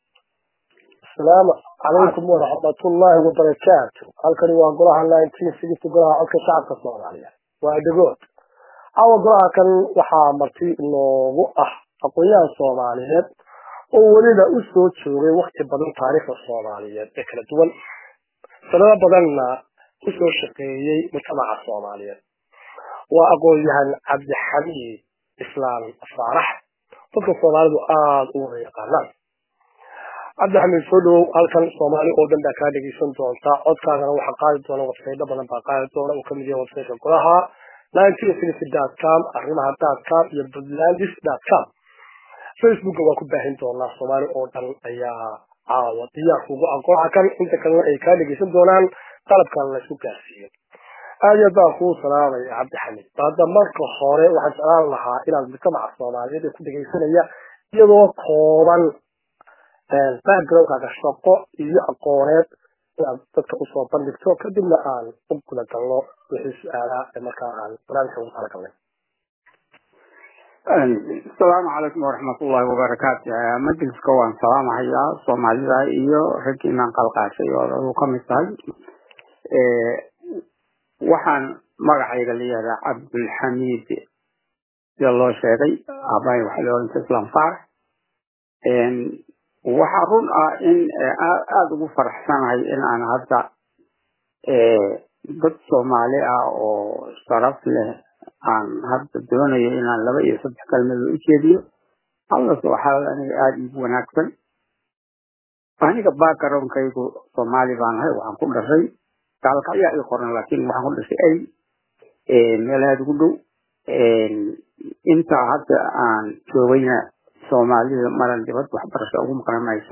Dhagayso Waraysiga Aqoonyahan